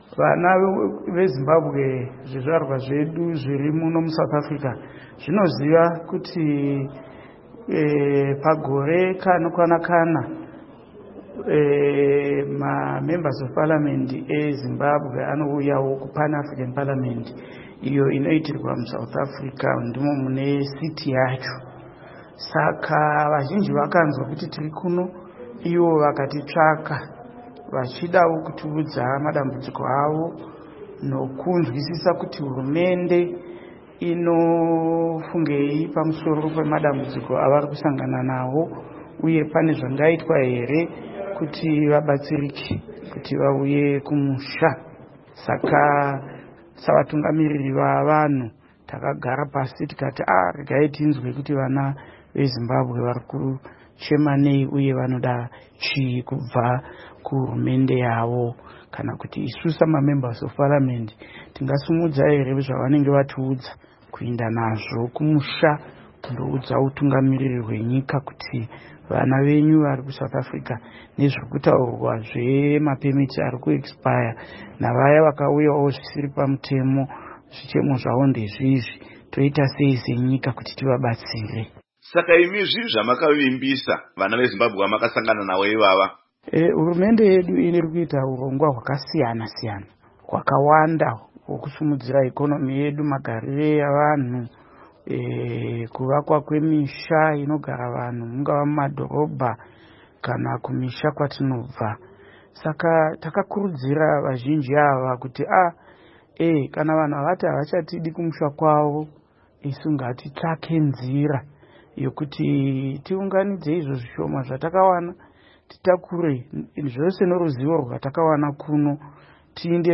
Hurukuro naVaPupurai Togarepi